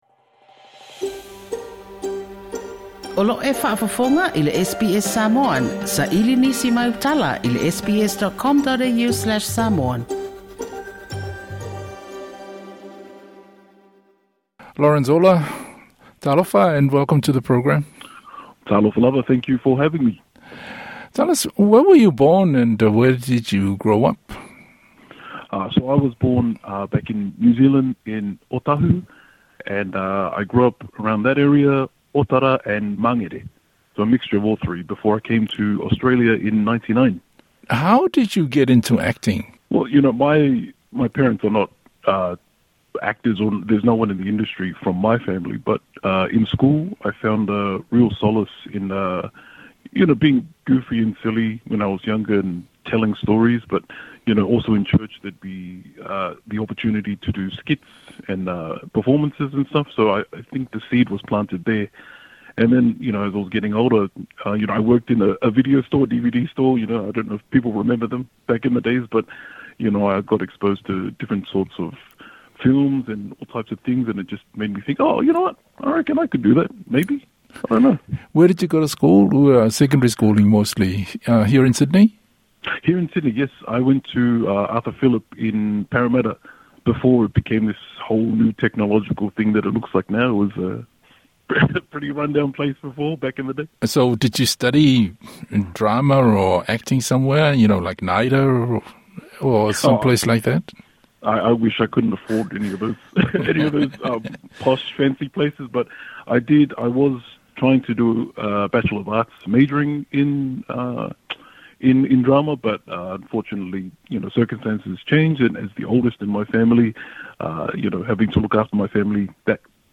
SBS Samoan